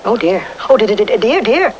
The voices of Pooh and his friends